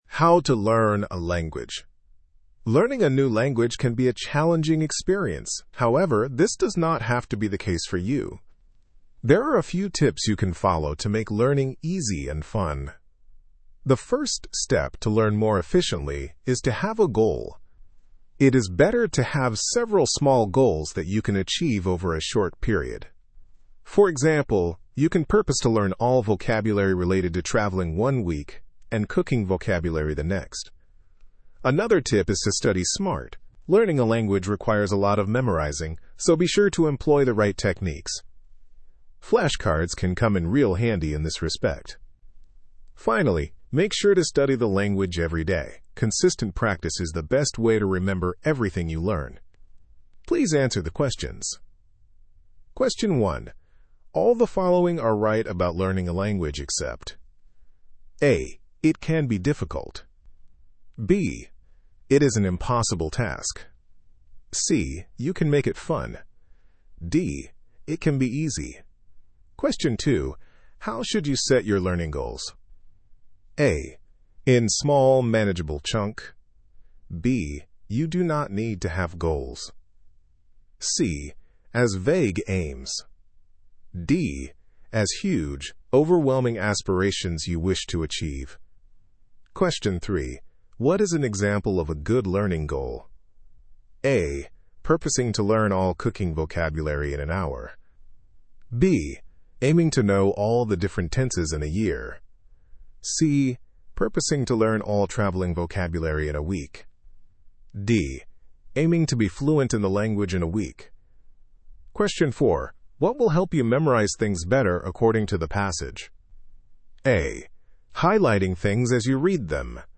Estados Unidos
Inglaterra